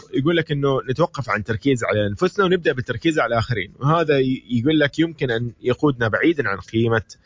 saudi_radio.mp3